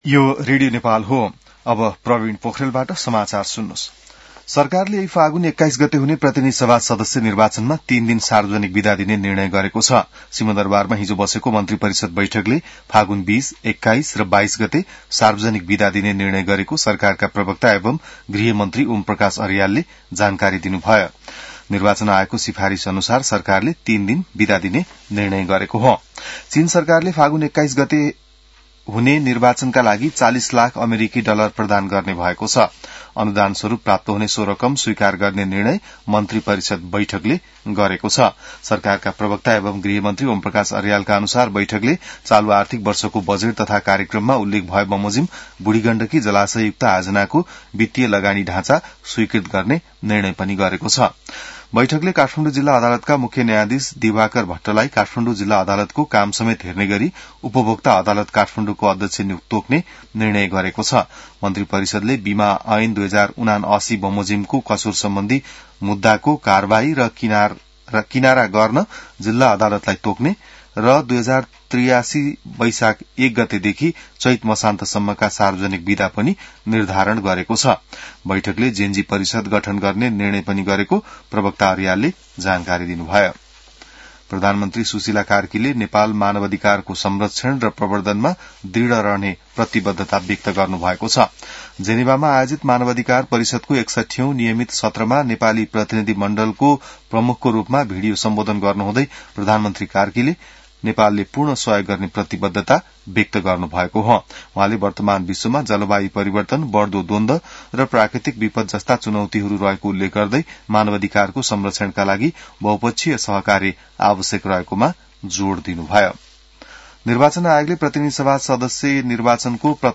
बिहान ६ बजेको नेपाली समाचार : १३ फागुन , २०८२